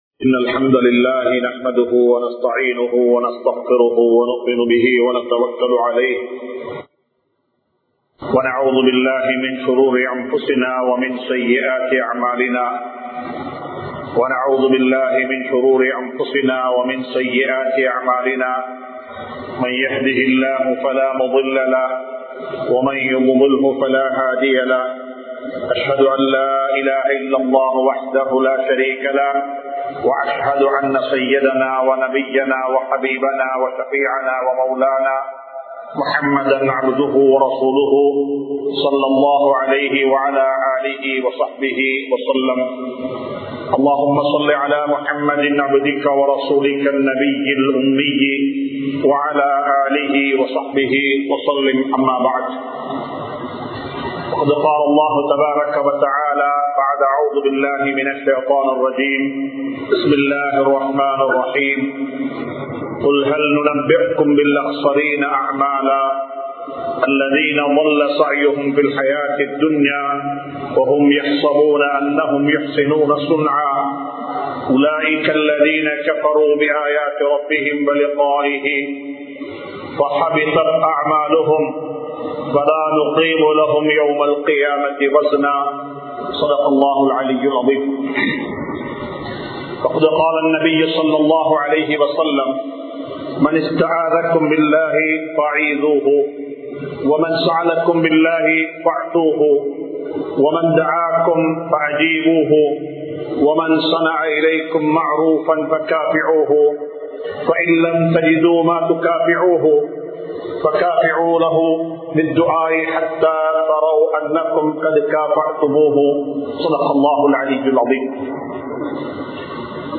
Nabi(SAW)Avarhalin Mun Maathirihal (நபி(ஸல்) அவர்களின் முன்மாதிரிகள்) | Audio Bayans | All Ceylon Muslim Youth Community | Addalaichenai